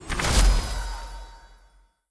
snd_ui_get.wav